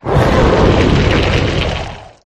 coalossal_ambient.ogg